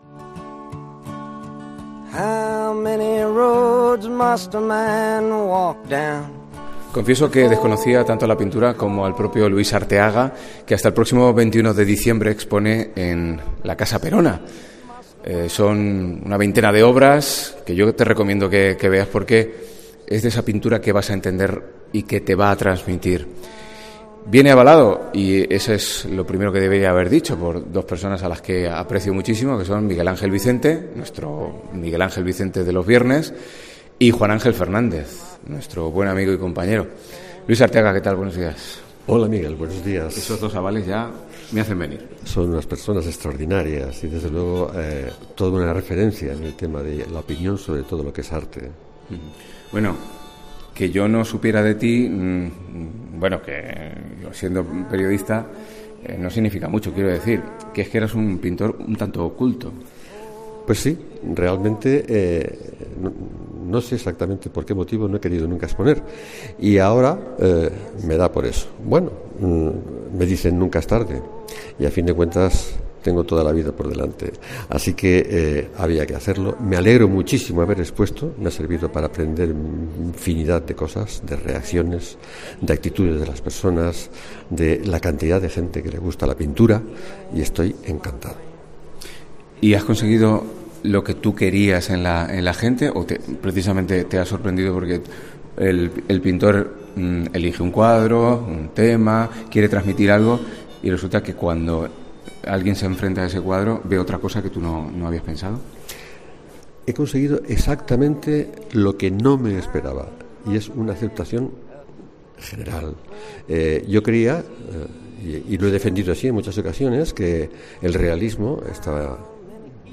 Hemos hablado con él, paseando por esta exposición y hemos conocido algo más de este pintor de cuadros escondidos... hasta ahora.